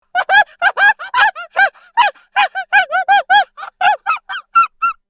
Chimpanzee Laughter Botão de Som